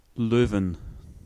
Leuven (IPA: ['lø:vən],
Nl_be-Leuven.ogg